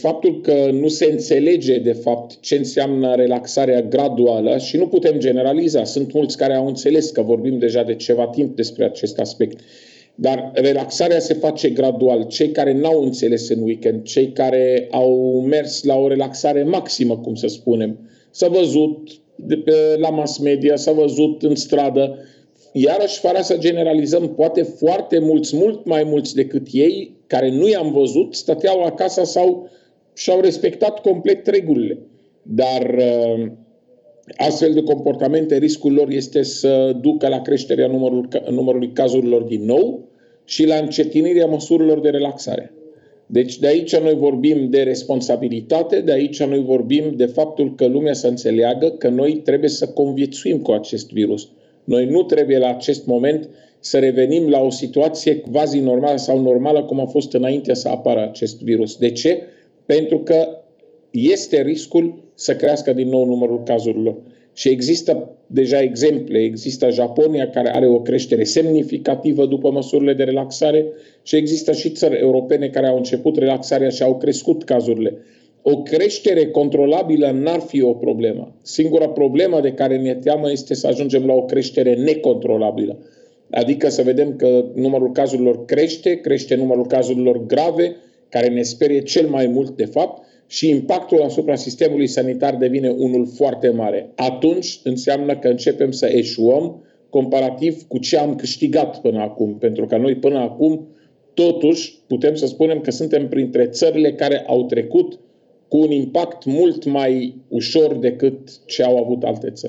“Unii nu au înțeles ce este relaxarea graduală. Unii au mers la relaxare maximă”, a spus Raed Arafat, luni seară, într-o intervenție la Digi 24.